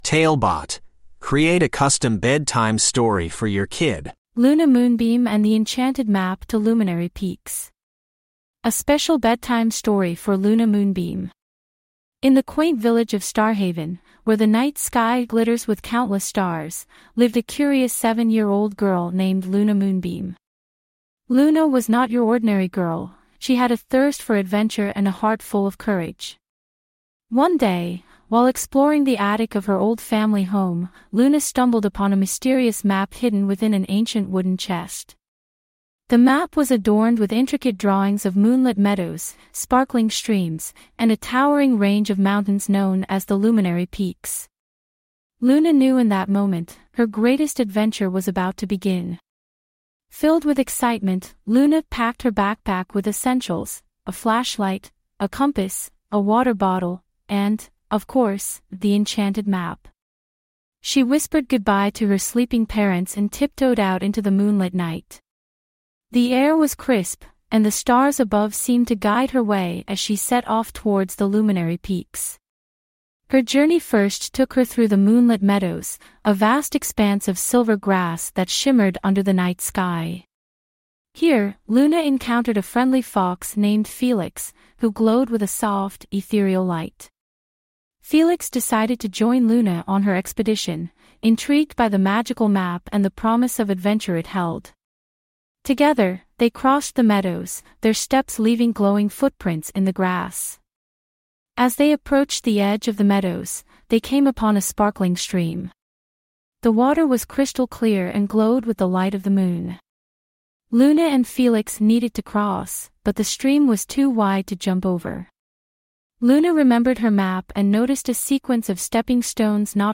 TaleBot Bedtime Stories
TaleBot AI Storyteller